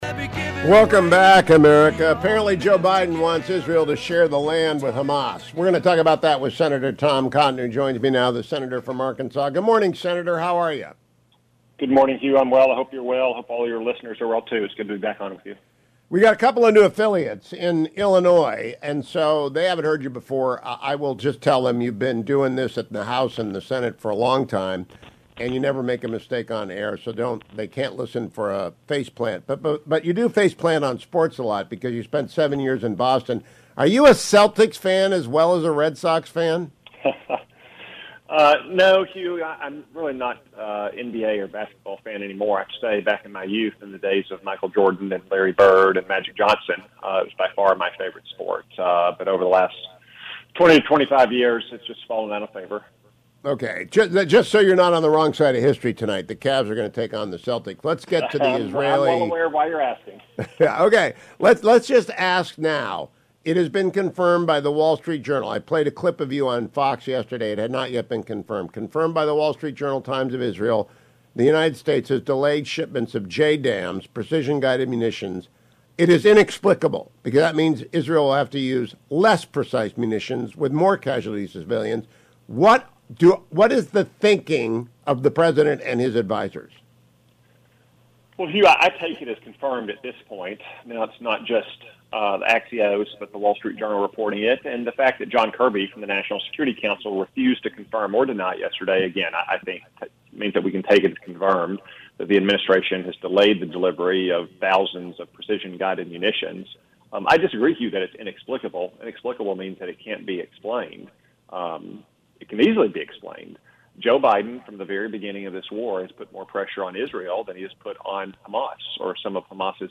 Senator Tom Cotton joined me this AM to discuss the disastrous policies pursued by President Biden against Israel, inlcuding now the cut-off of precision munitions: